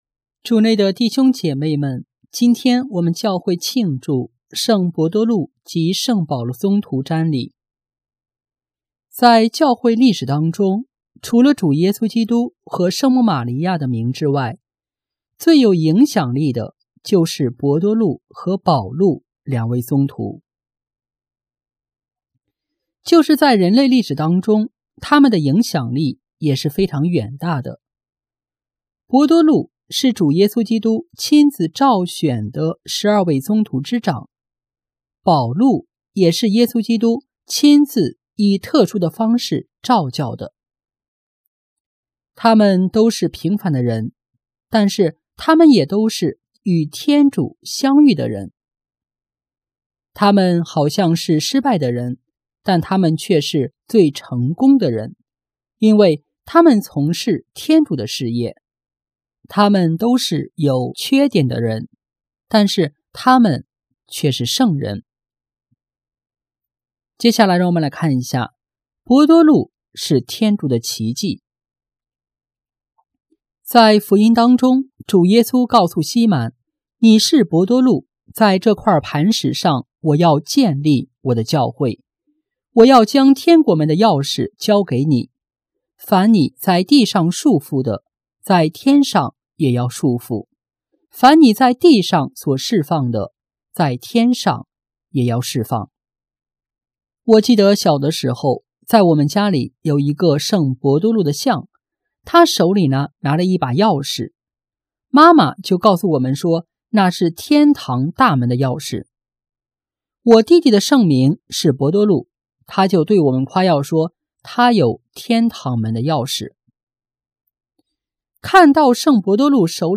【主日证道】| 伯多禄保禄，天主的奇迹（圣伯多禄及圣保禄宗徒瞻礼）